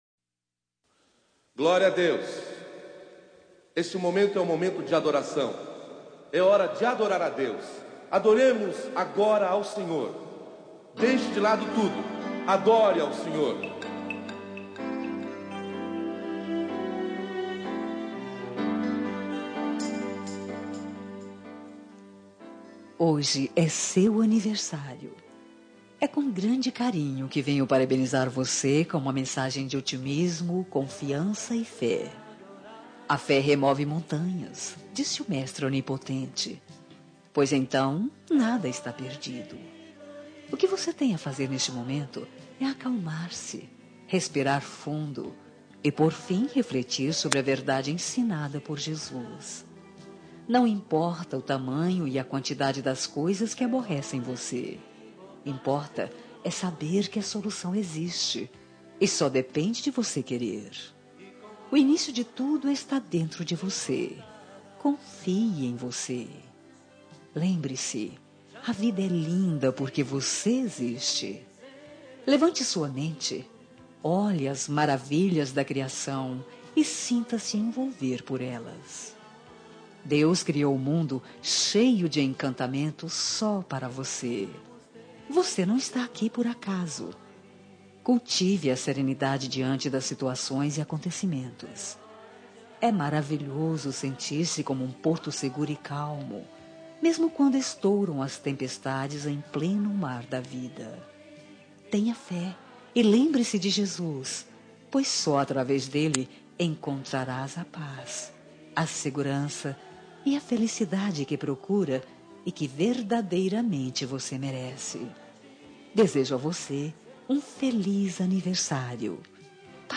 Telemensagem de Otimismo – Voz Feminina – Cód: 191
74A 26 Otimismo (GOSPEL).mp3